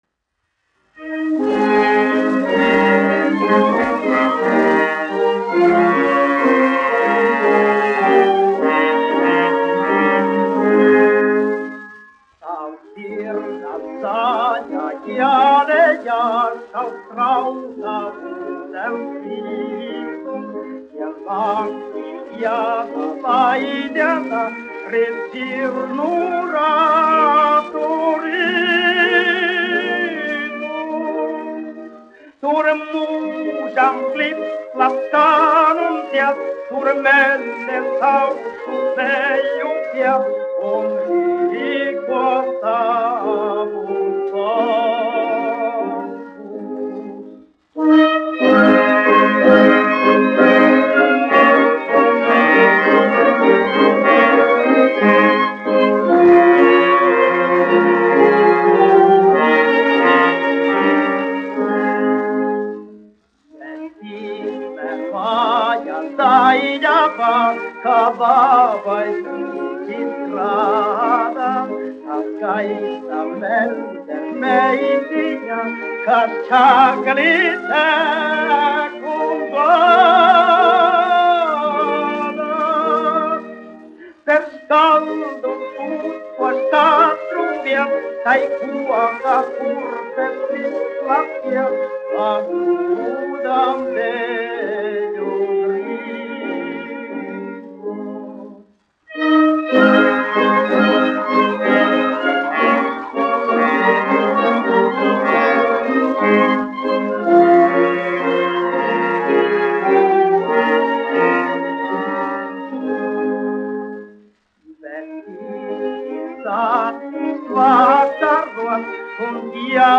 1 skpl. : analogs, 78 apgr/min, mono ; 25 cm
Populārā mūzika -- Latvija
Skaņuplate